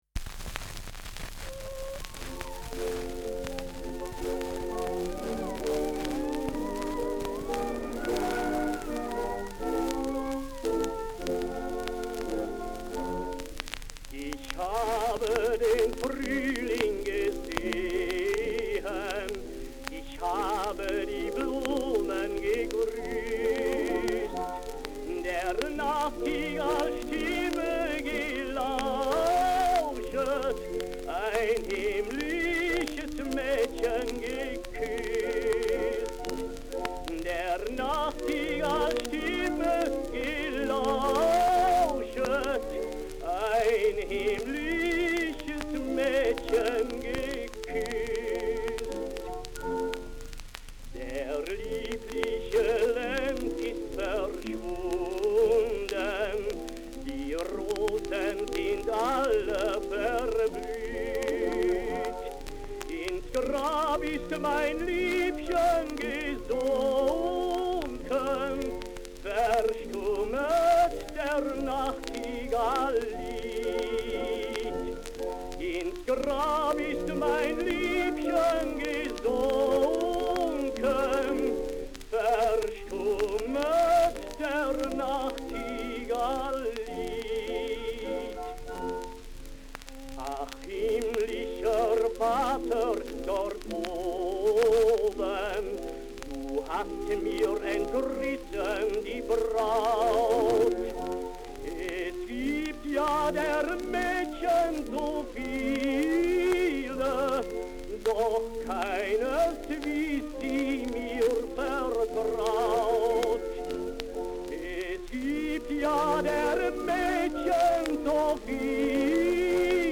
Schellackplatte
Stärkeres Grundrauschen : Durchgehend leichtes bis starkes Knacken : Verzerrt an lauten Stellen
[unbekanntes Ensemble] (Interpretation)